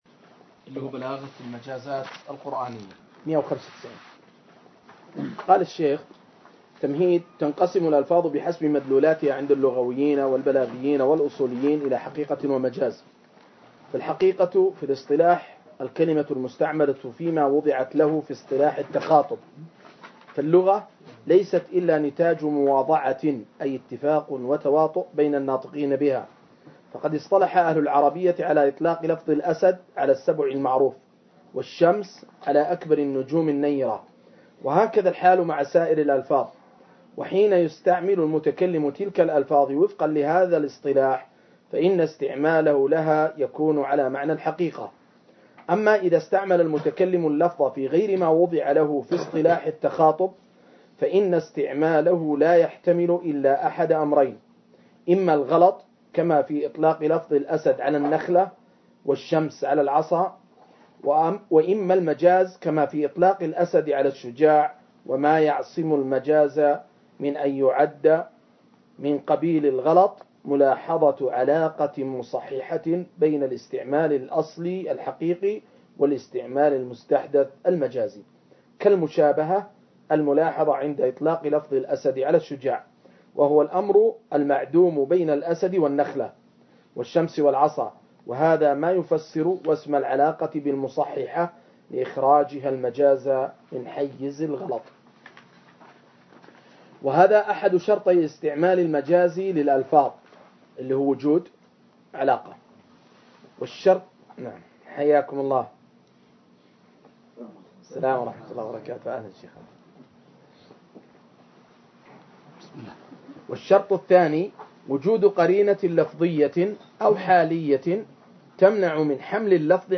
قراءة وتعليق